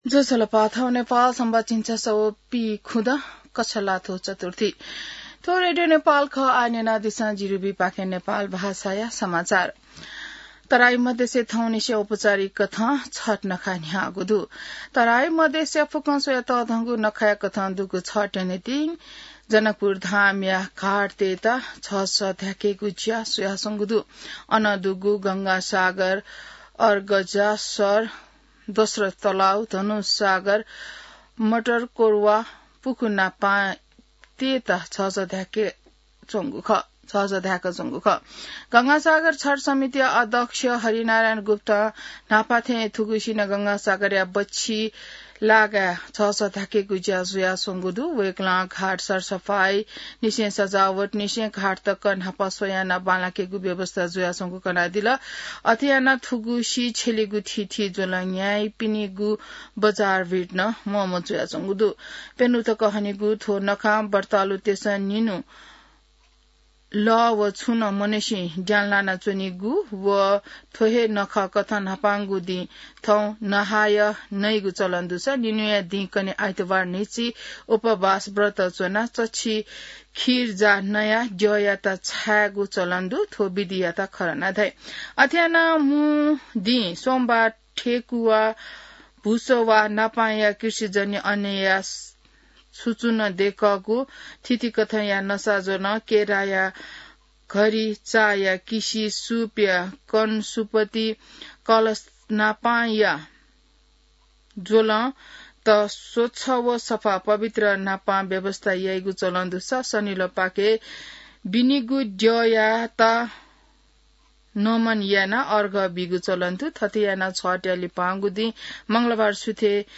नेपाल भाषामा समाचार : ८ कार्तिक , २०८२